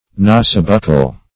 Search Result for " nasobuccal" : The Collaborative International Dictionary of English v.0.48: Nasobuccal \Na"so*buc"cal\, a. [Naso + buccal.]